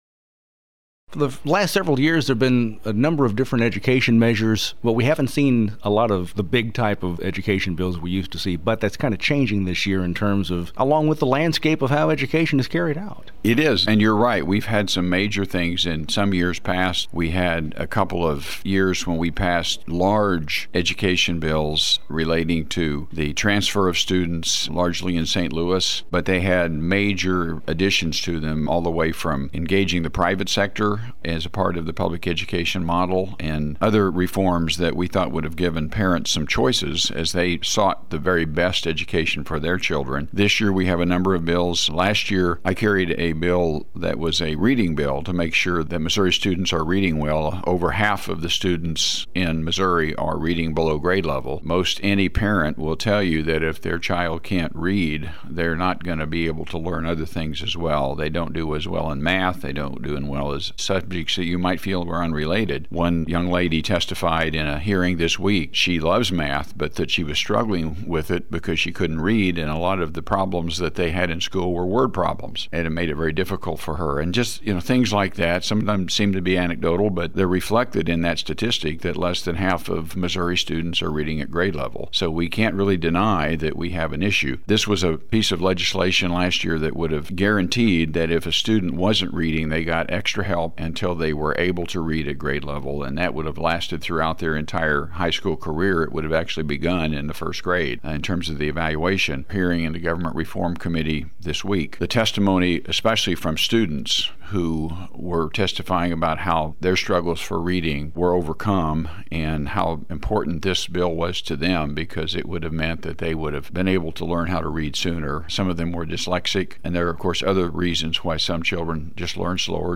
JEFFERSON CITY — State Sen. Ed Emery, R-Lamar, discusses Senate Bill 349, legislation that seeks to require each local school district and charter school to have a policy for reading intervention plans for any pupils in grades kindergarten through four; Senate Bill 271, a measure that would transfer the authority of the State Board of Education and the Department of Elementary and Secondary Education to regulate charter schools to the Missouri Charter Public School Commission; and Senate Bill 160, which would establish the Missouri Empowerment Scholarship Accounts Program.